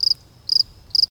sfx_crickets.ogg